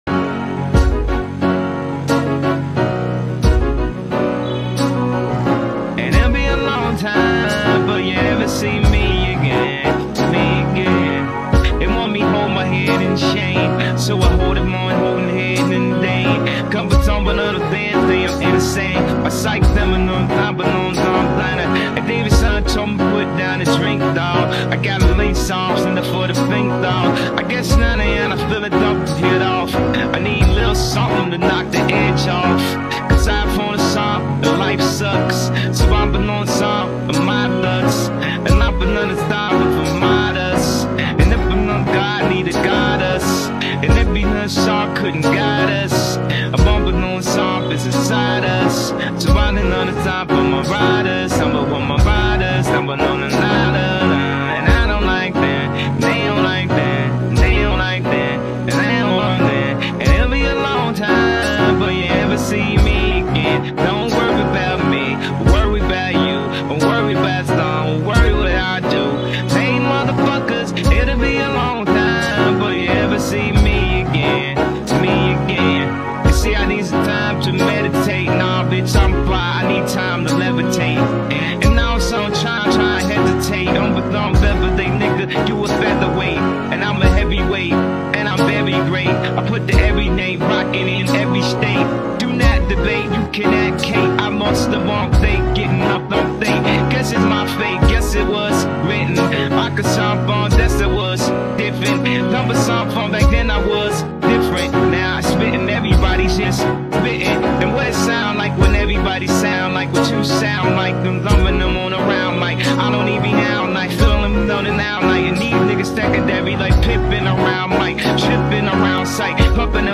Genero: Hip-Hop/Rap